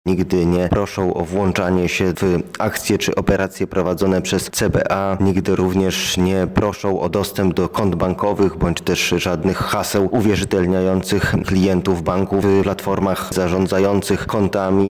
Funkcjonariusze CBA nigdy nie dzwonią z takimi żądaniami – mówi rzecznik prasowy Ministra Koordynatora Służb Specjalnych Stanisław Żaryn: